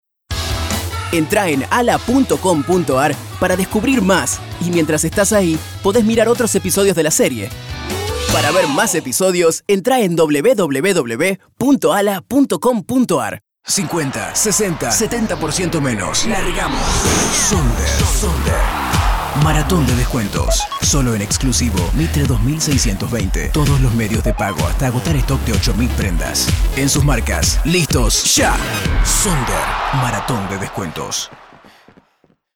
Young, fresh, engaging, interpretive voice. Neutral Latin American Spanish, Mexican and Argentinian.
Sprechprobe: Sonstiges (Muttersprache):